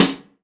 SNARE 101.wav